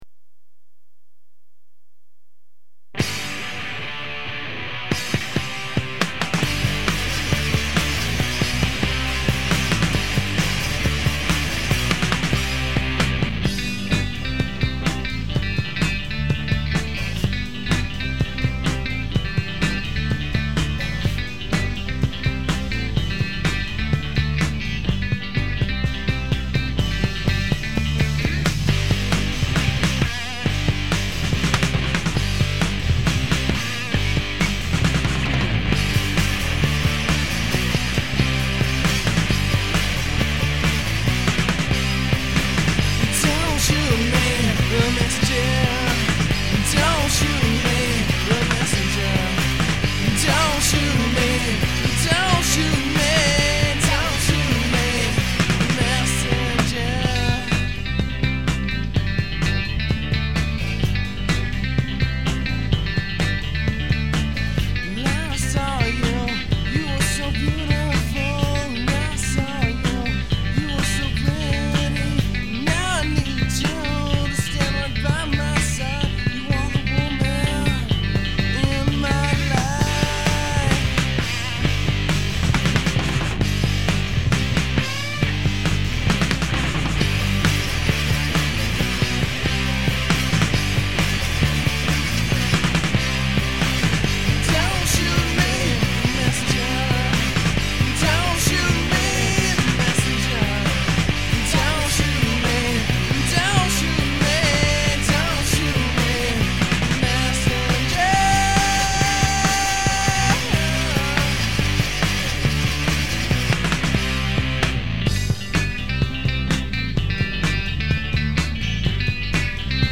more of a pop-rock feel.